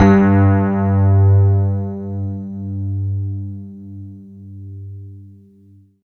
47 RHOD G2-L.wav